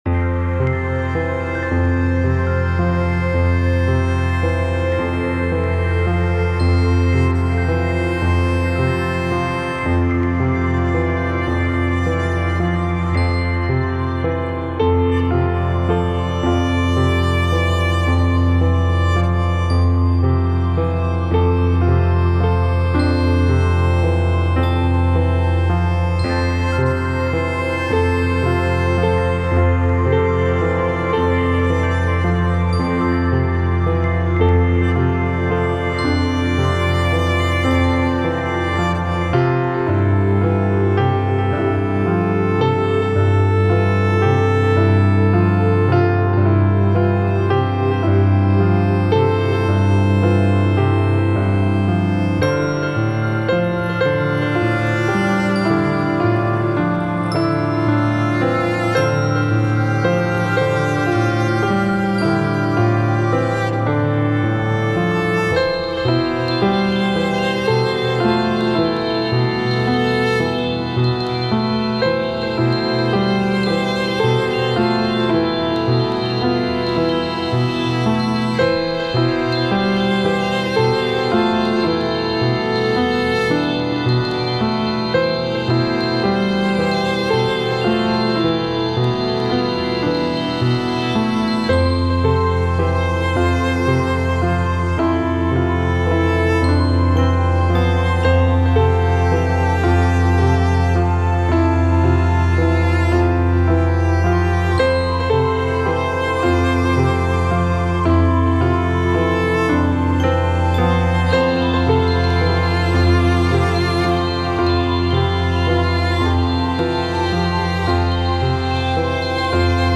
Concencia (Electronic Chamber Music)